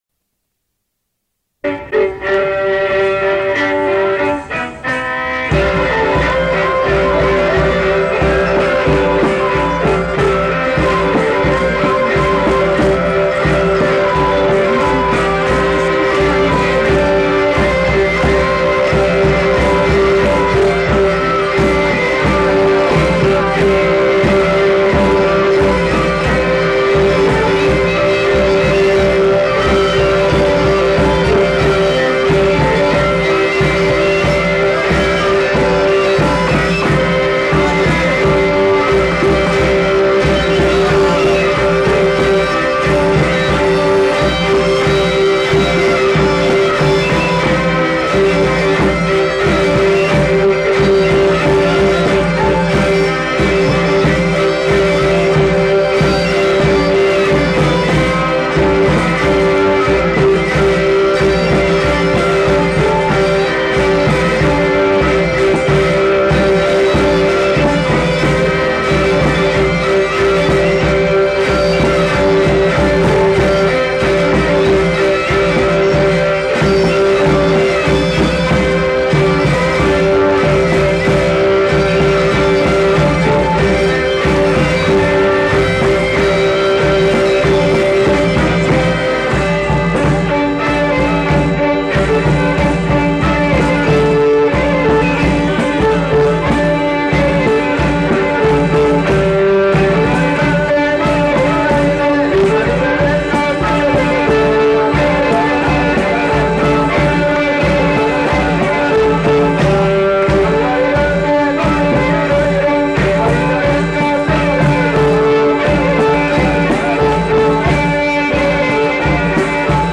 Aire culturelle : Pays d'Oc
Lieu : Pinerolo
Genre : morceau instrumental
Instrument de musique : violon ; vielle à roue ; boha ; percussions
Danse : scottish
Notes consultables : Enchaînement de deux thèmes.